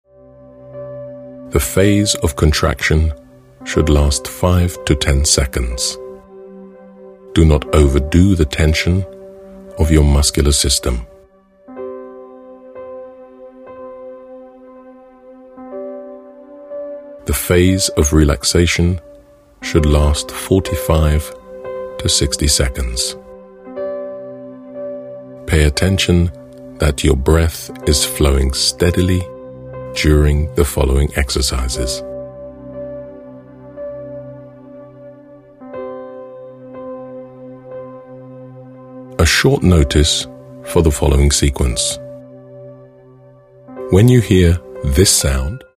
This Audiobook is a guide for your self-studies and learning.